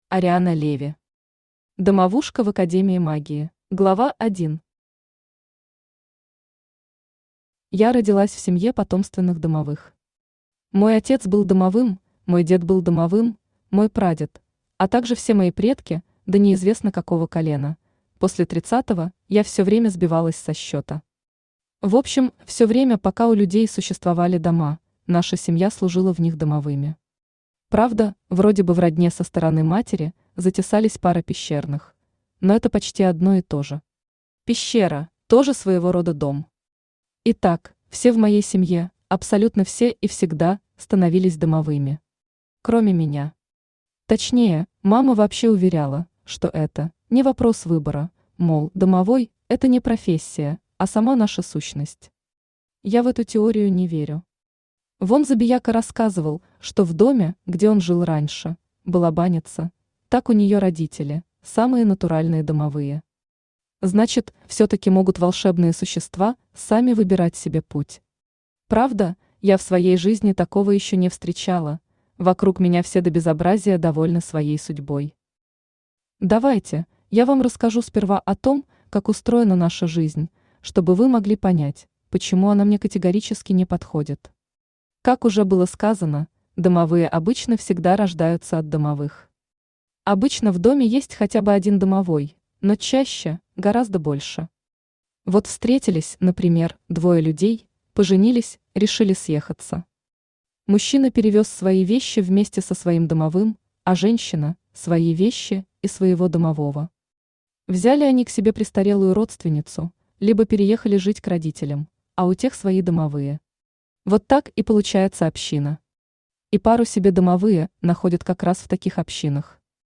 Aудиокнига Домовушка в Академии Магии Автор Ариана Леви Читает аудиокнигу Авточтец ЛитРес.